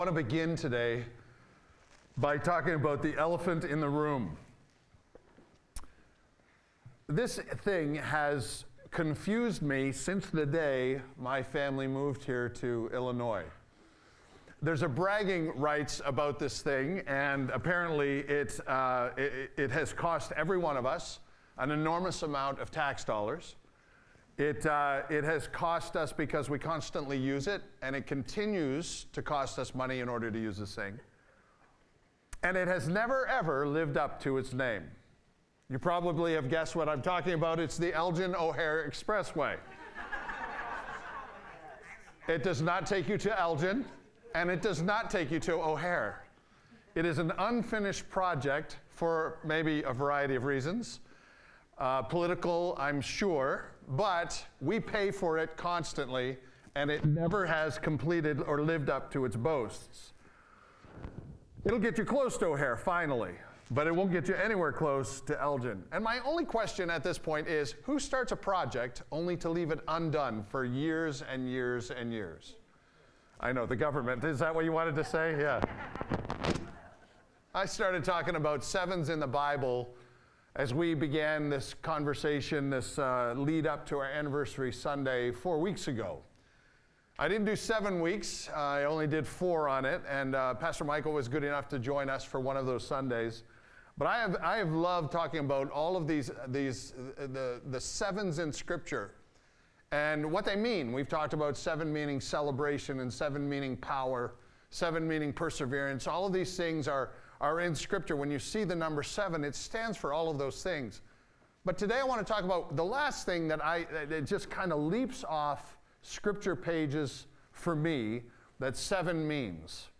For the last Sunday of the Sevens series, we dive into how throughout life all hard work comes to completion. Today was the 7 year anniversary of Village Church East, and how much the church has grown with God and his people emphisizes the great plans God has for us.